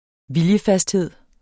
Udtale [ -ˌfasdˌheðˀ ]